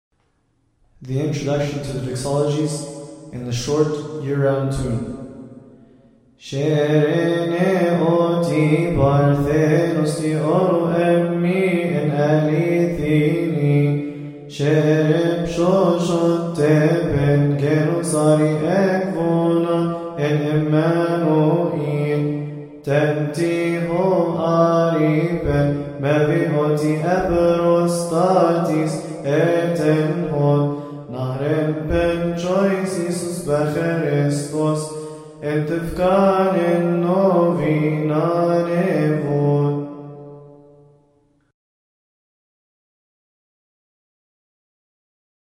All hymns must be chanted according to the Higher Institute of Coptic Studies.
annual and